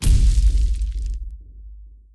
Add explosion sound (CC0)
sounds_explosion_02.ogg